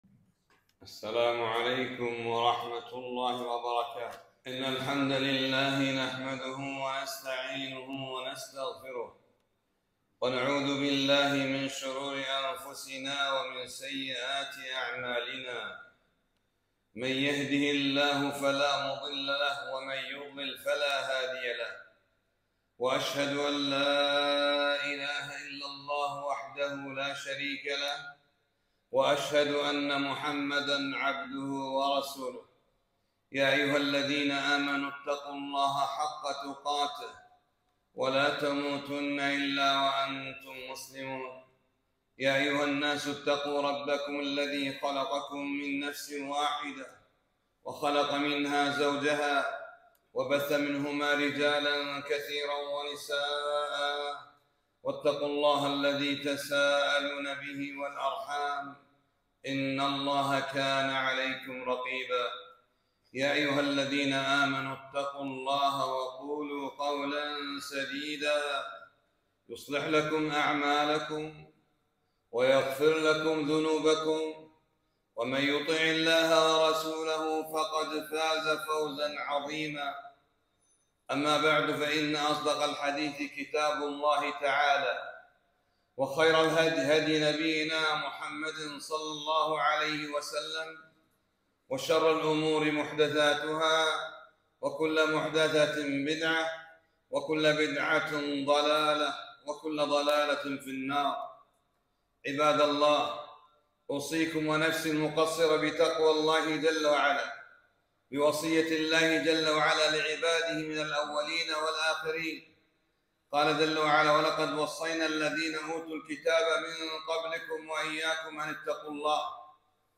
خطبة - إياكم والدخول على النساء